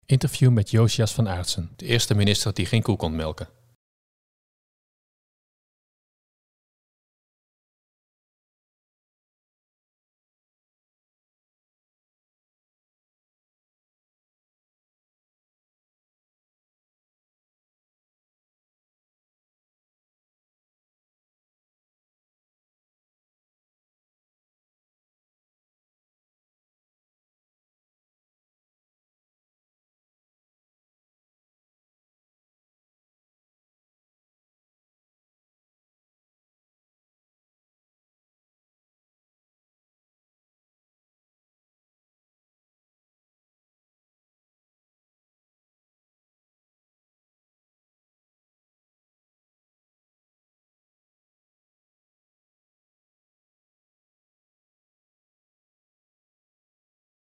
Interview met Jozias van Aartsen